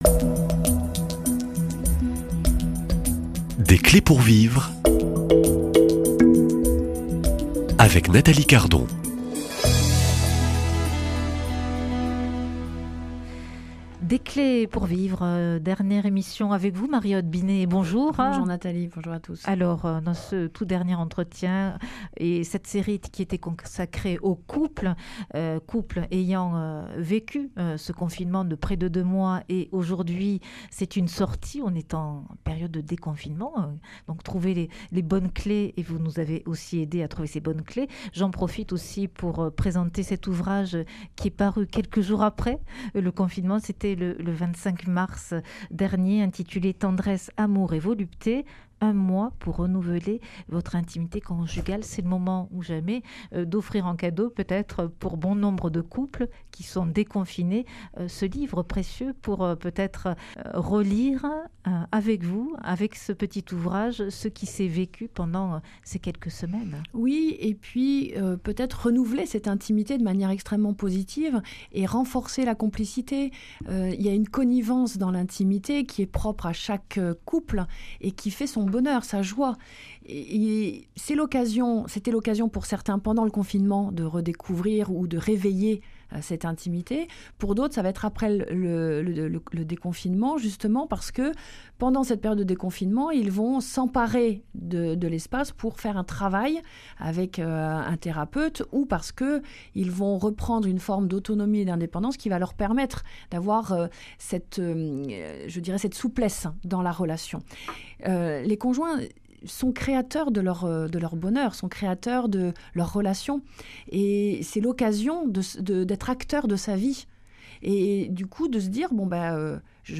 Invitée !